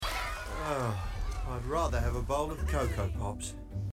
COCO POPS COMMERCIALS